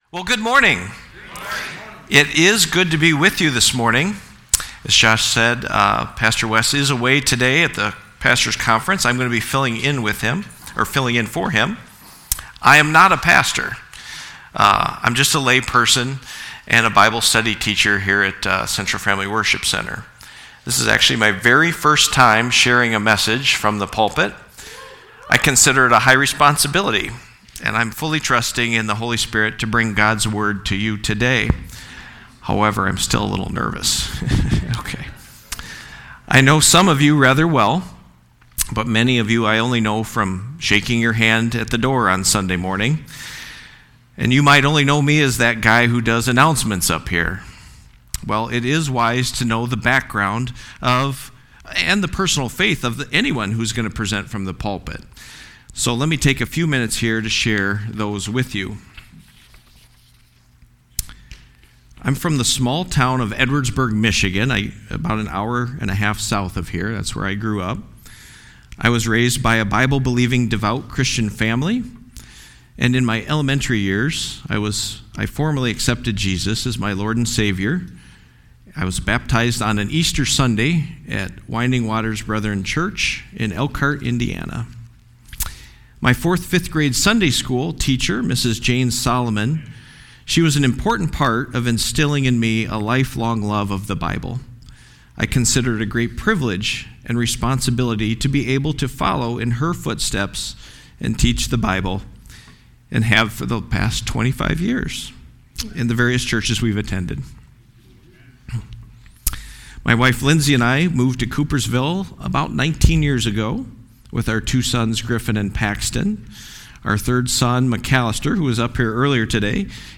Sermon-1-18-26.mp3